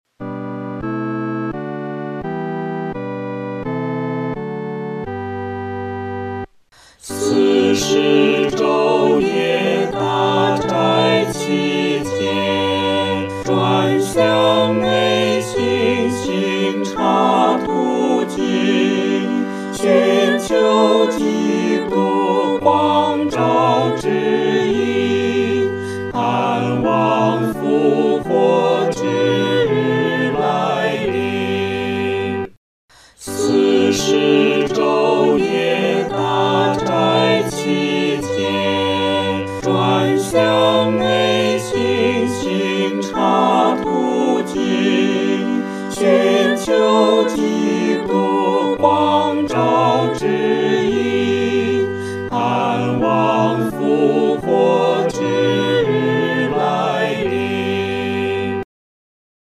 合唱
四声
这首诗歌充满着虔敬和恳切，我们在弹唱时的速度不宜太快。